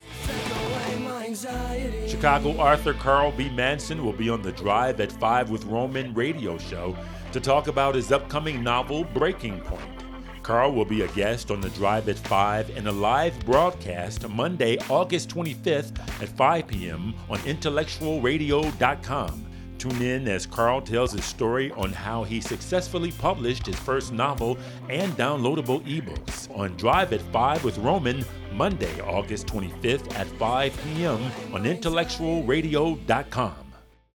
Roman Drive at 5 Radio Interview
ROMAN DRIVE AT 5 - 15 SEC. COMMERICAL - Copy.mp3